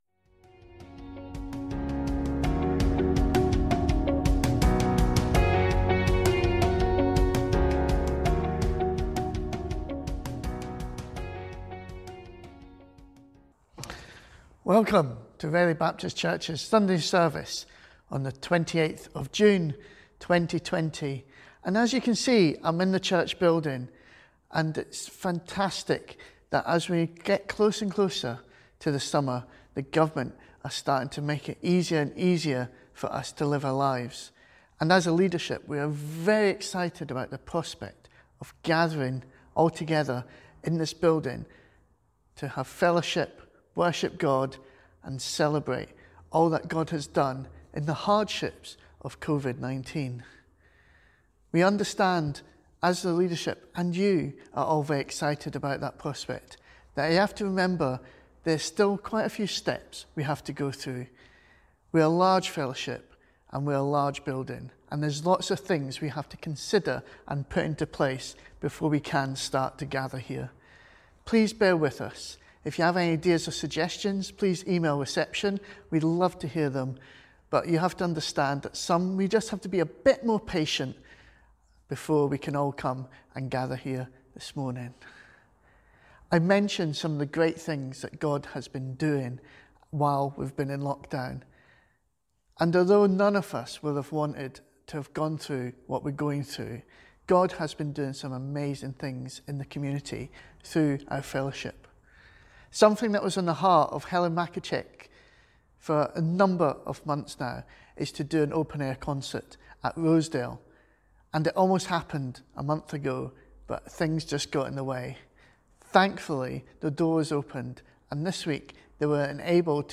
A message from the series "Faith for Lifes Journey."